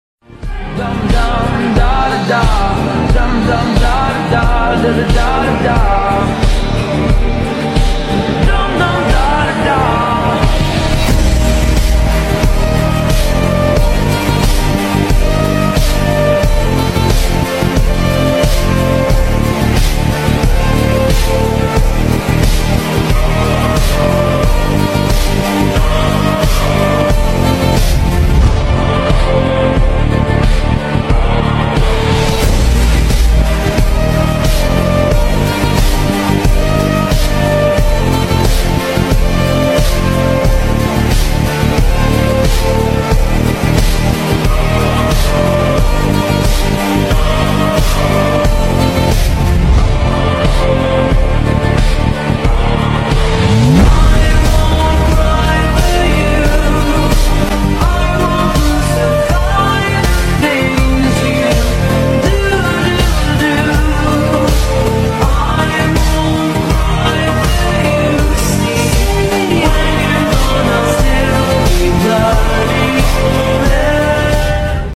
Army girls training hard sound effects free download